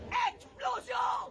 Play, download and share magnifestante explosion original sound button!!!!
magnifestante-explosion.mp3